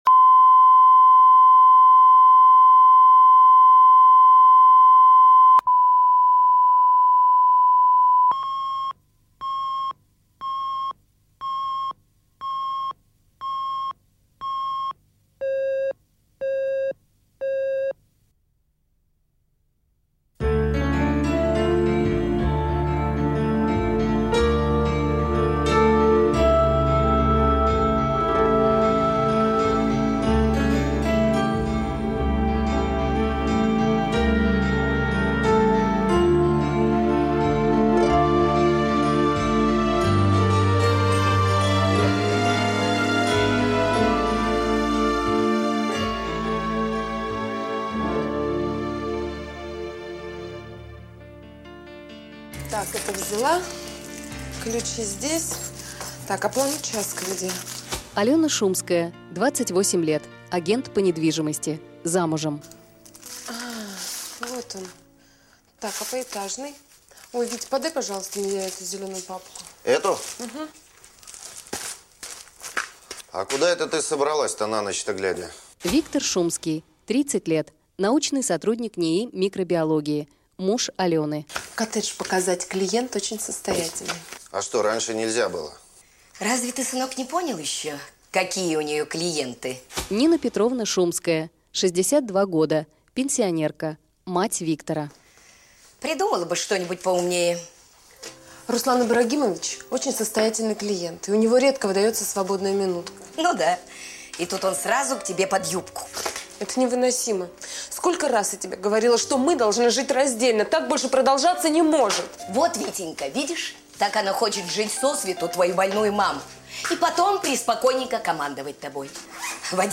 Аудиокнига Семья на разъезд | Библиотека аудиокниг